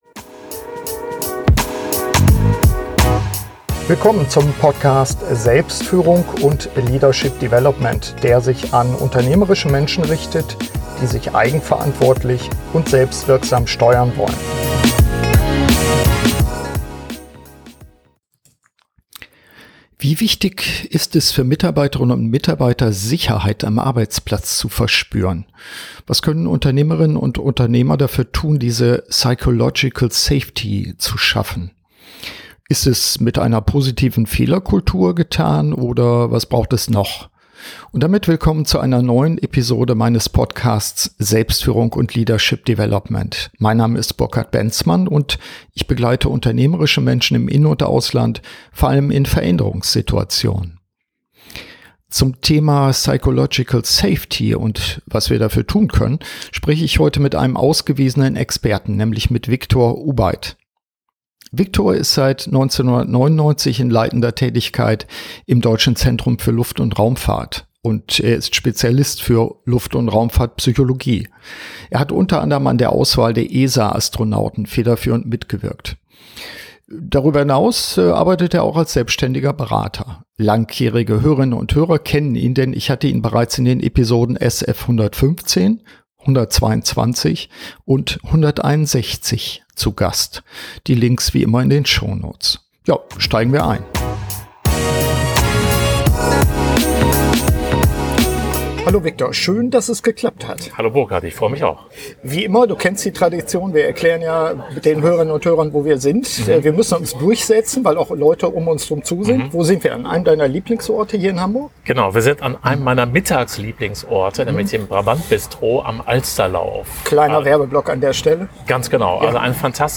Zum Thema Psychological Safety spreche ich heute mit einem ausgewiesenen Experten